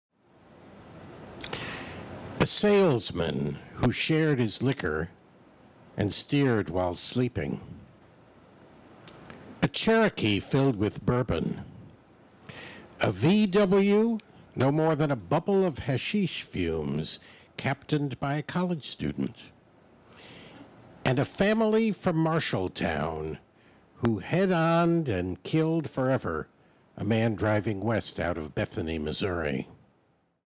Available Now! Three Object Lessons, an Audio Book
Wally-Reads-Johnson-Preview_1-2.mp3